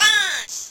dancerPunch.wav